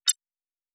pgs/Assets/Audio/Sci-Fi Sounds/Interface/Error 05.wav at master
Error 05.wav